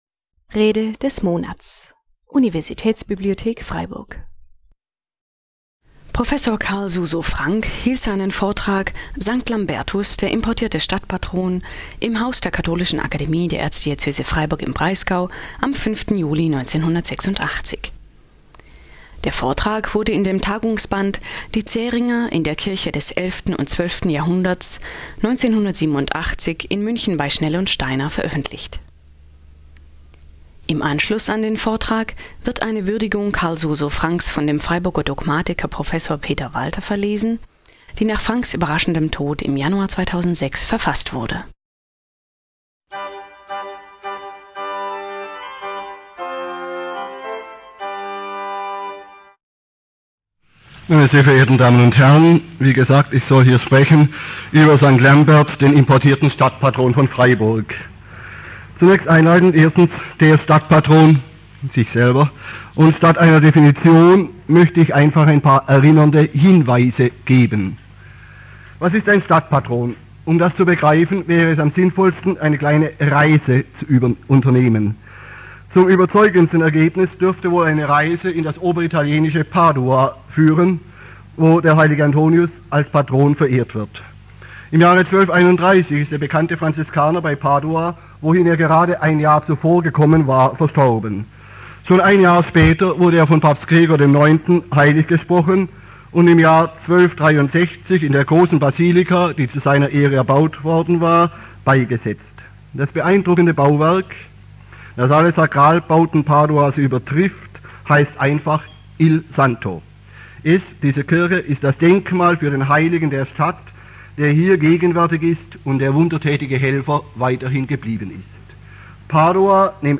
Sankt Lambertus - der importierte Stadtpatron (1986) - Rede des Monats - Religion und Theologie - Religion und Theologie - Kategorien - Videoportal Universität Freiburg
im Haus der Katholischen Akademie der Erzdiözese Freiburg im Breisgau am 5. Juli 1986.